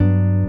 gtr_14.wav